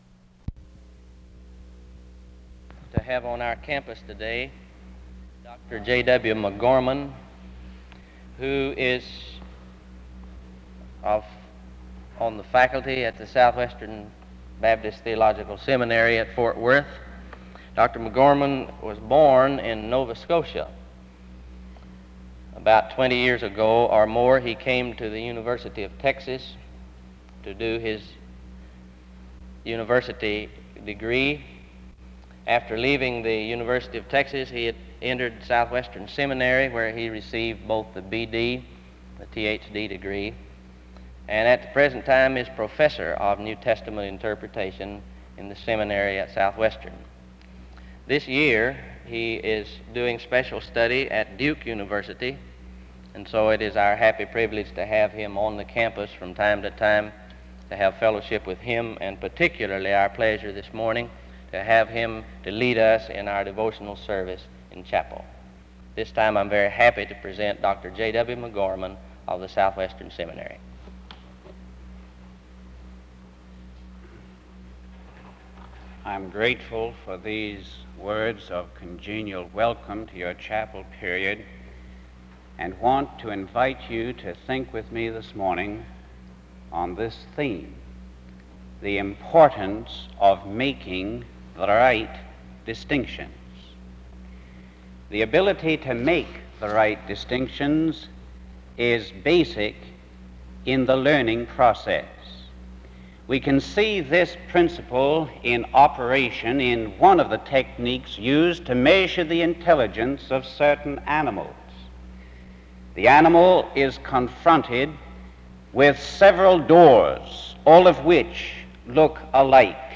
SEBTS Chapel
SEBTS Chapel and Special Event Recordings SEBTS Chapel and Special Event Recordings